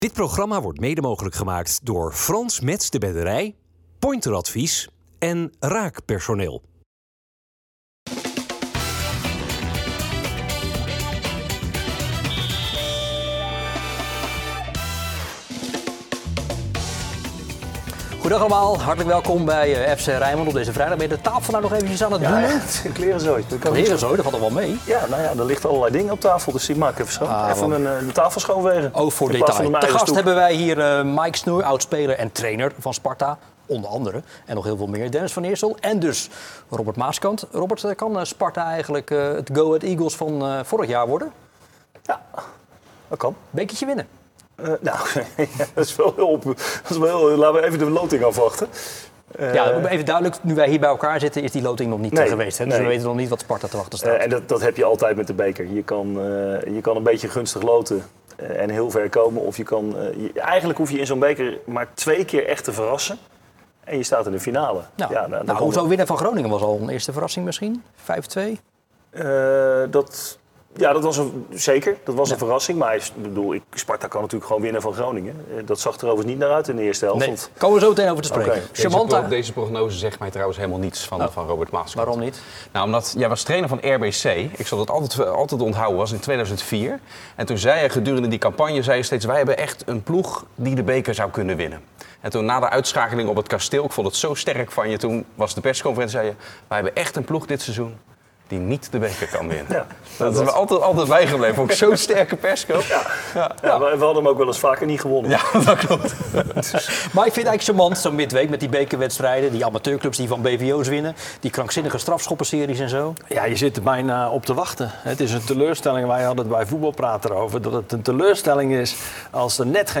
FC Rijnmond is dé voetbaltalkshow over het Rotterdamse profvoetbal.